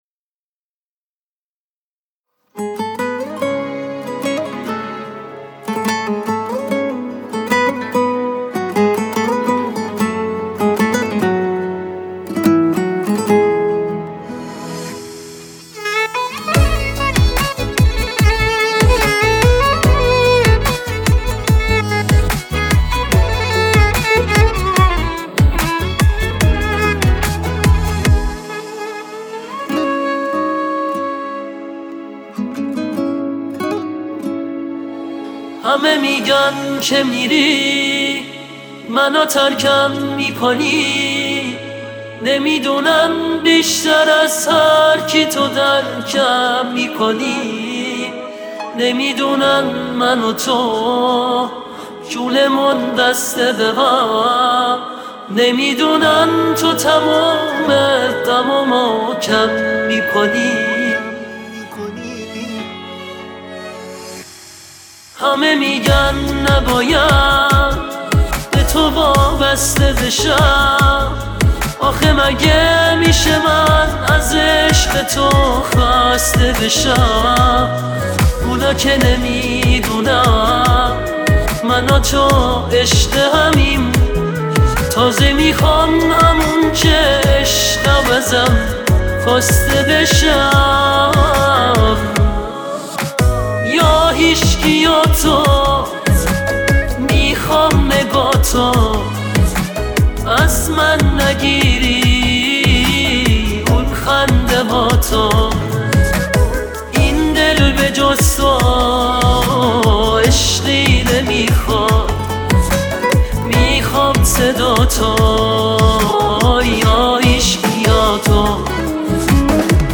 آهنگ پاپ ایرانی